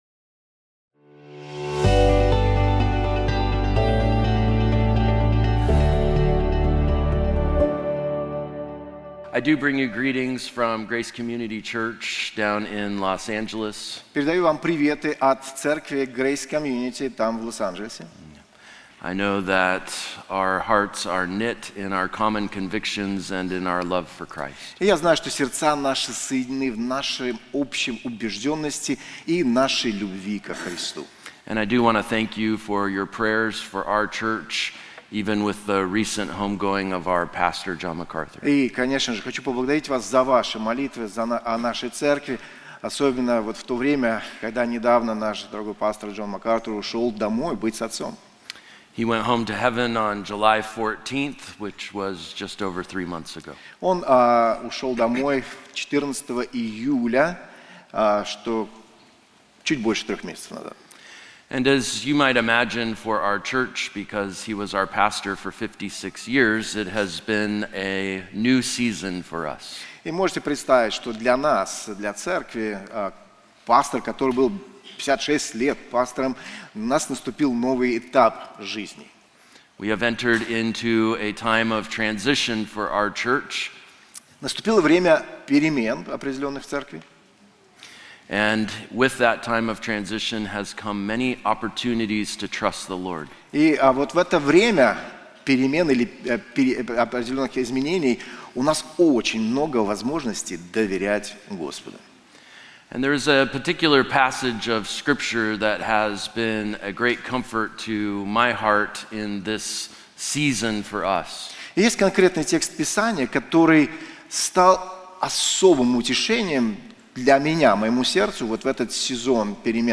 В этой проповеди мы изучаем удивительный отрывок из Евангелия от Марка 6:45-52, где Иисус, Суверенный Спаситель, организует божественный урок для Своих учеников на Галилейском море. После чудесного насыщения 5 000 человек Христос посылает Своих учеников в бурю, открывая их слабости и Свою бесконечную силу.